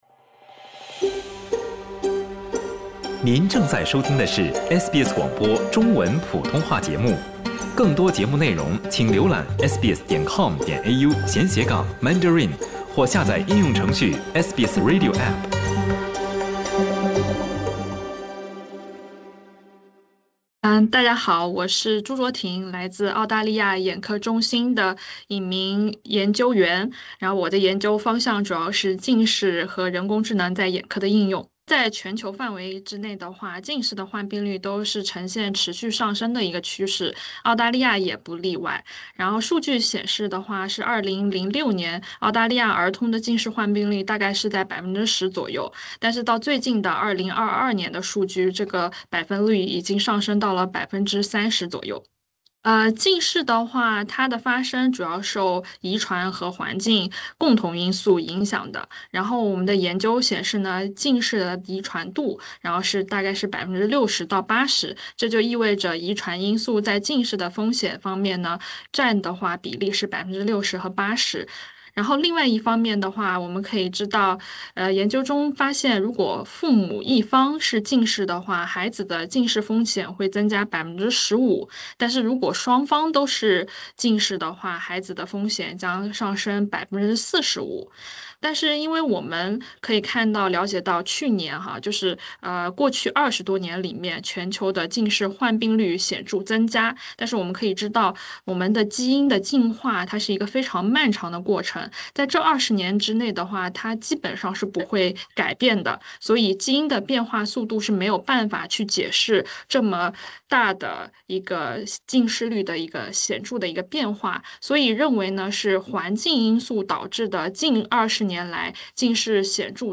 请点击播客，收听采访详情。